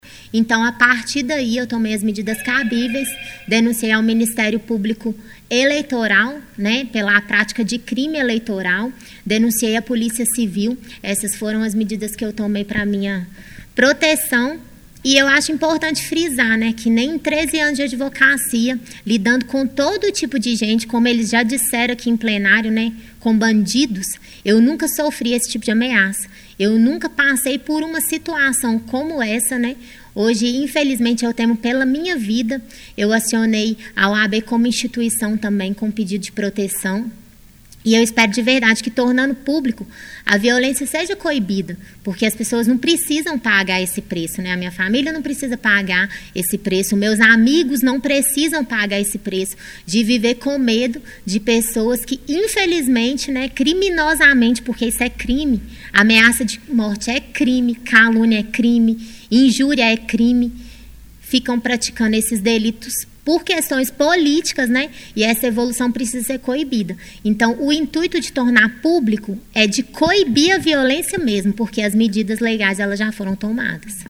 Ela convidou a imprensa para uma coletiva onde falou sobre o que vem sofrendo nos últimos meses.